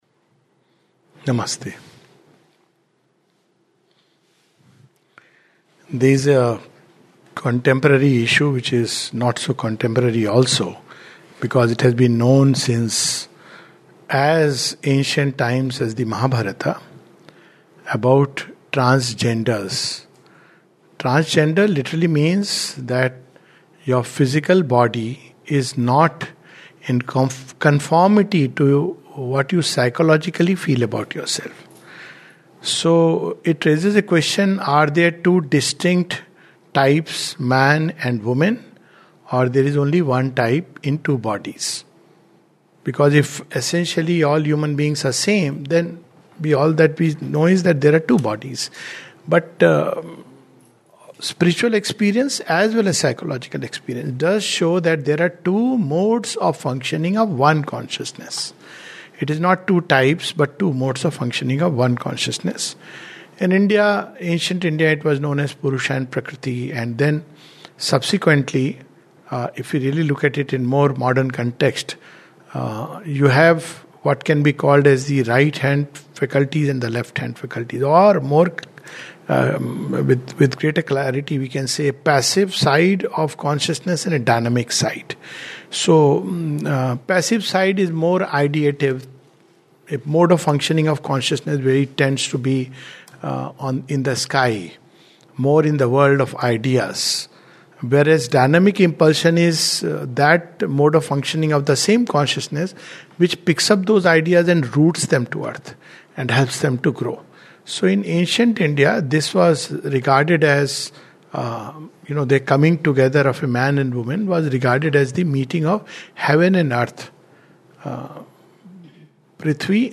In this talk we take a look at the truth of genders to get a deeper understanding of it.